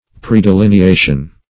Predelineation \Pre`de*lin`e*a"tion\, n.